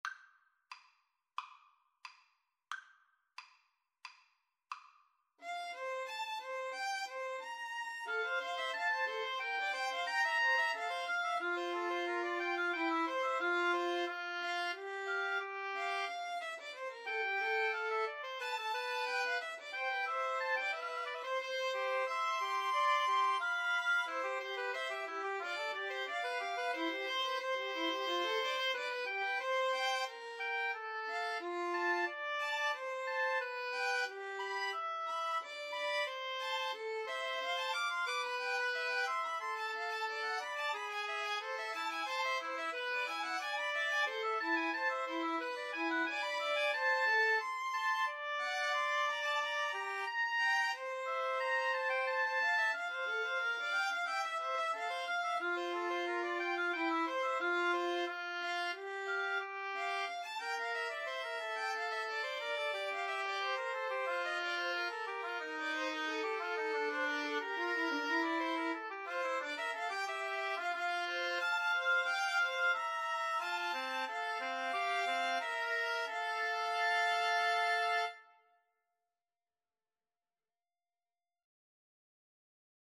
OboeViolinCello
F major (Sounding Pitch) (View more F major Music for Mixed Trio )
Classical (View more Classical Mixed Trio Music)